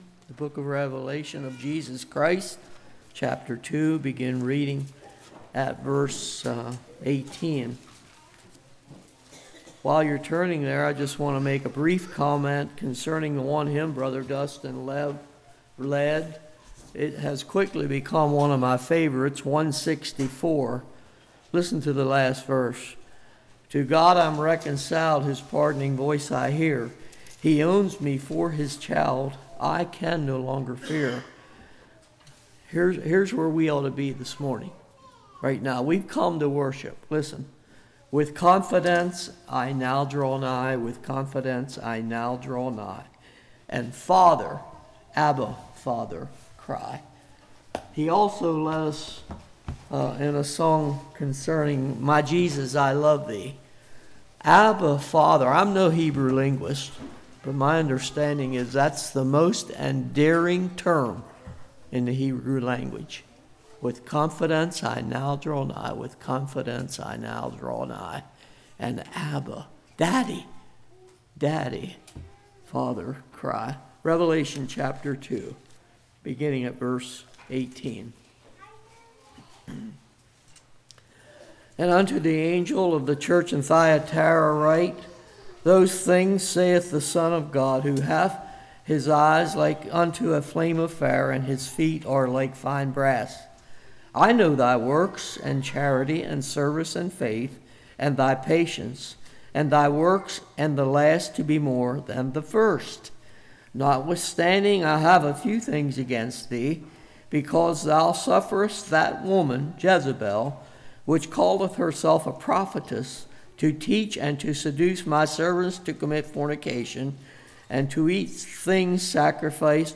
Passage: Revelation 2:18-29 Service Type: Morning